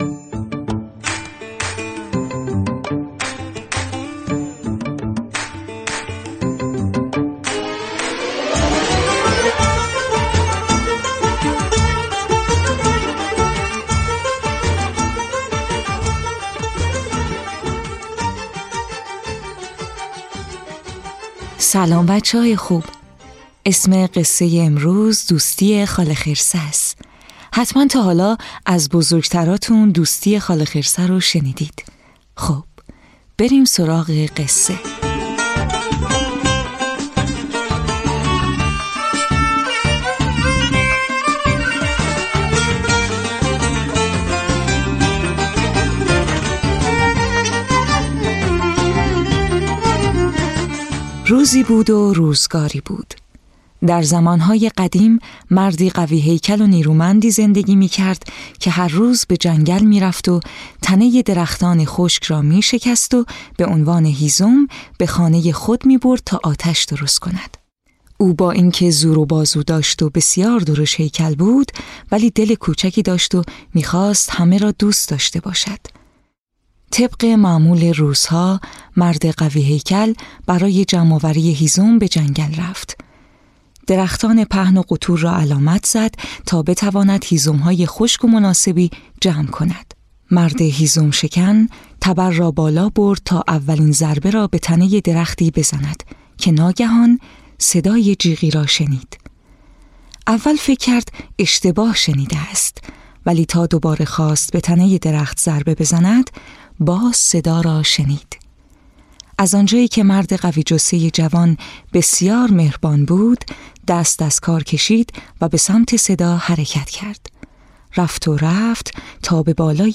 قصه های کودکانه صوتی - این داستان: دوستی خاله خرسه
تهیه شده در استودیو نت به نت